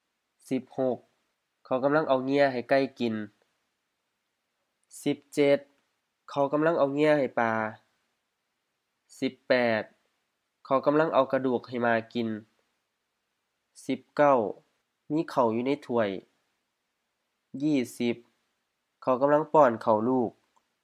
IsaanPronunciationTonesThaiEnglish/Notes